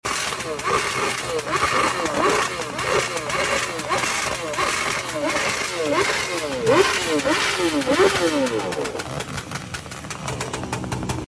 Afternoon Session S1000RR is burning